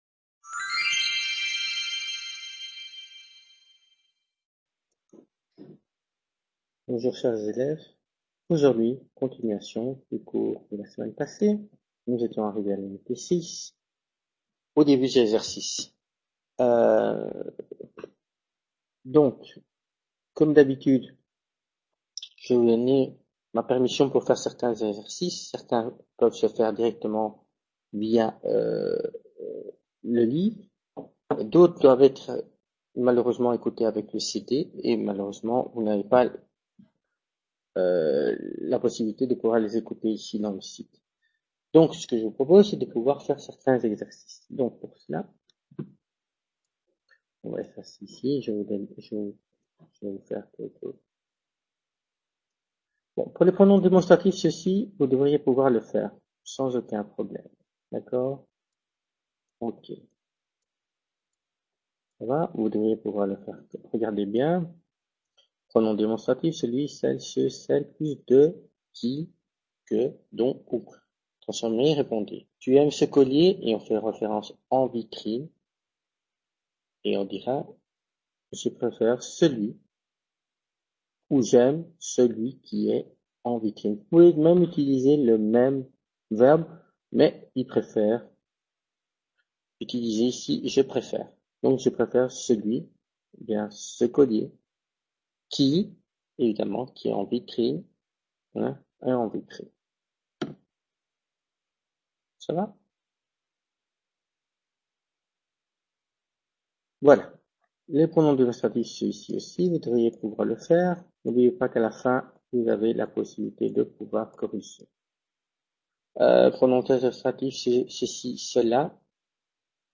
Clase de Francés Niveau Intermédiaire 30/04/2015